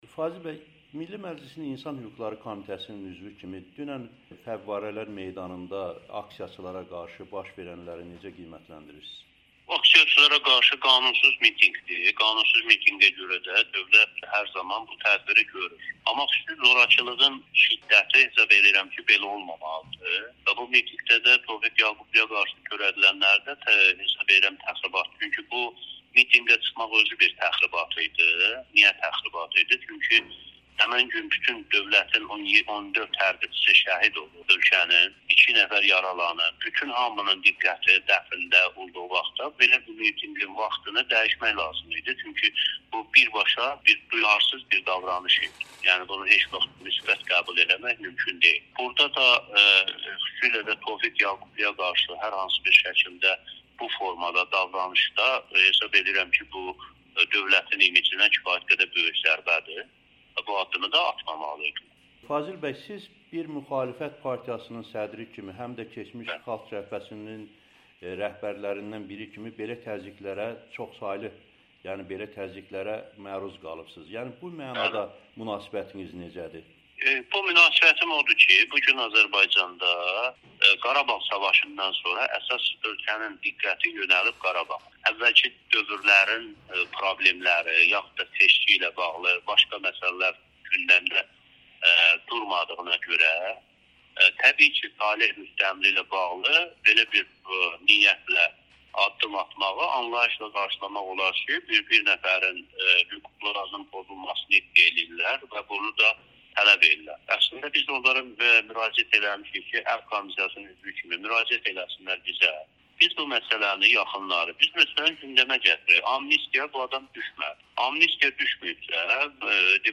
Milli Məclisin İnsan Hüquqları Komitəsinin üzvü Fazil Qəzənfəroğlu Amerikanın Səsinə müsahibəsində bildirib ki, aksiya qanusuz keçirilib.